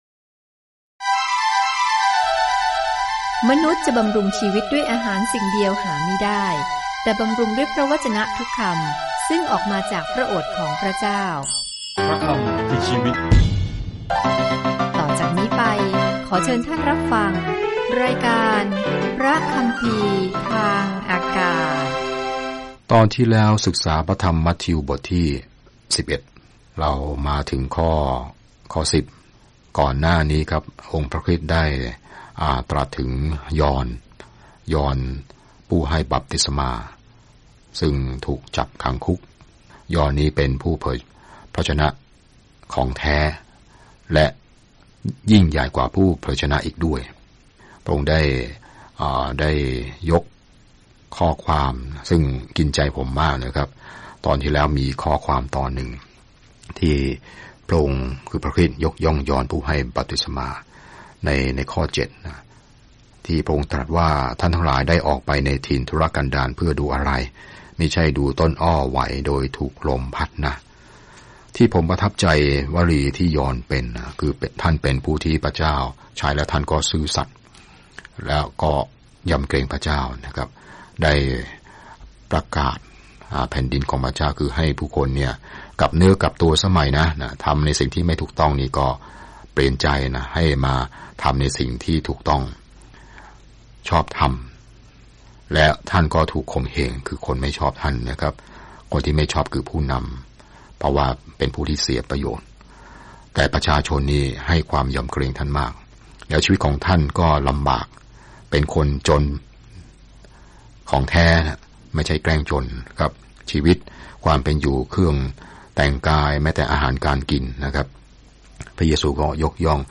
มัทธิวพิสูจน์ให้ผู้อ่านชาวยิวเห็นข่าวดีว่าพระเยซูคือพระเมสสิยาห์ของพวกเขาโดยแสดงให้เห็นว่าพระชนม์ชีพและพันธกิจของพระองค์ทำให้คำพยากรณ์ในพันธสัญญาเดิมเกิดสัมฤทธิผลอย่างไร เดินทางทุกวันผ่านมัทธิวในขณะที่คุณฟังการศึกษาด้วยเสียงและอ่านข้อที่เลือกจากพระวจนะของพระเจ้า